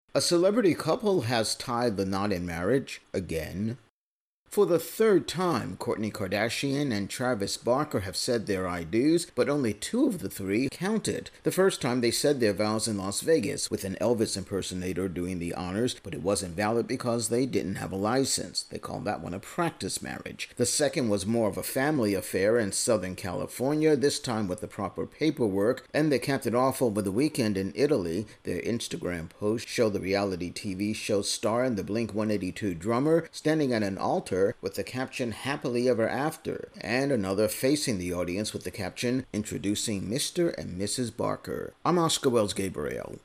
Kardashian-Barker Italy Wedding Intro+Voicer